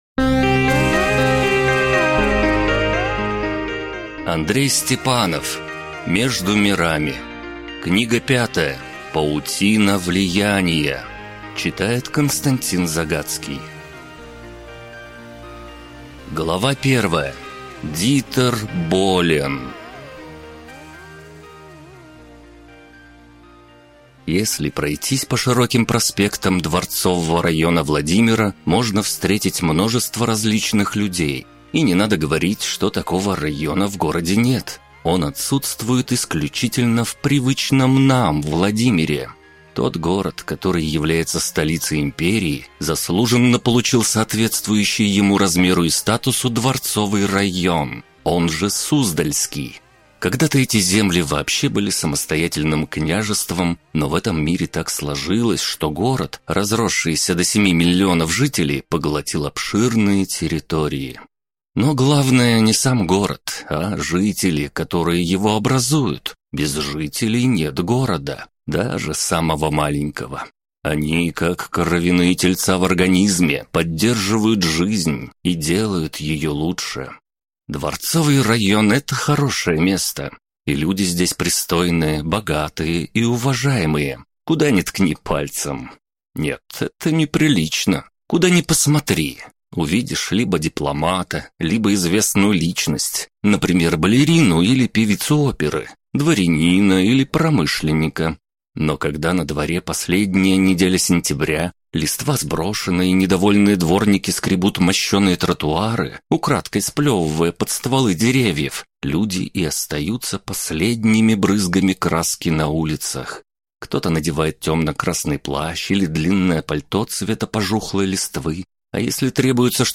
Аудиокнига Между мирами. Том 3 | Библиотека аудиокниг